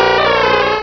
Cri de Tygnon dans Pokémon Rubis et Saphir.